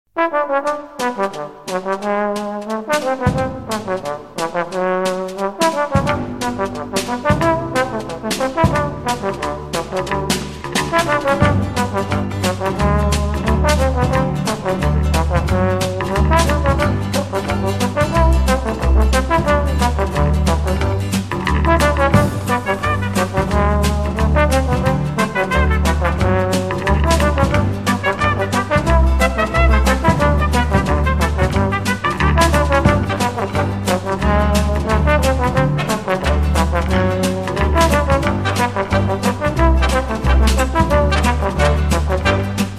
Brass Timbre test question 6